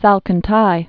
(sălkən-tī, sälkän-)